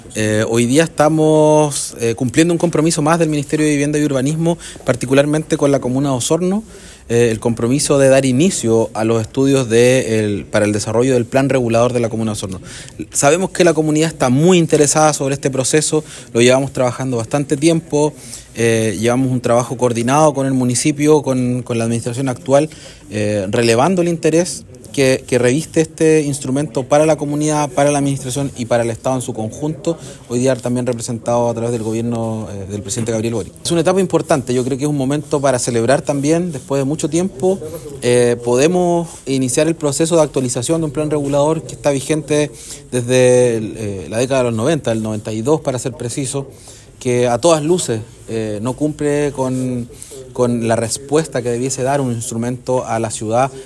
El Seremi de Vivienda, Fabián Nail, subrayó la importancia de este proceso, destacando que el plan regulador vigente data de hace más de 30 años, por lo que es esencial adaptarlo a las nuevas condiciones urbanas y ambientales de Osorno.